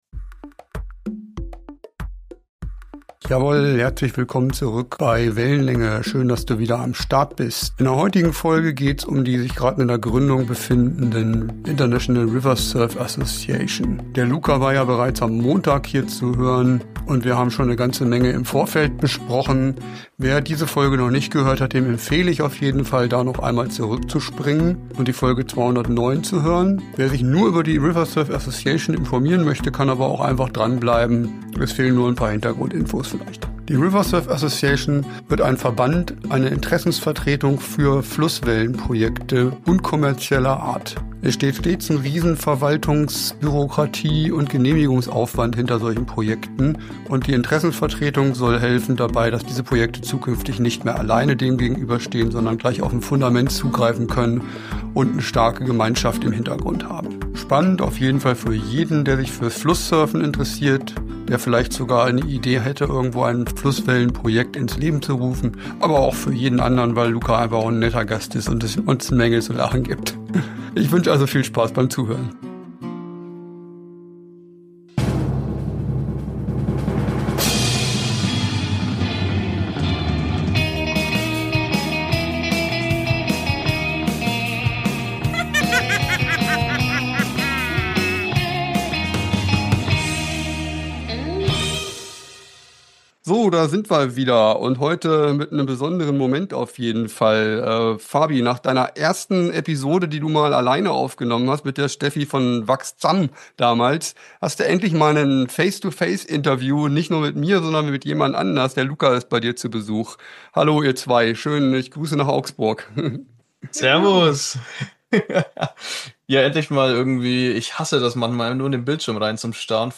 Heute gibt’s die Fortsetzung unseres Gesprächs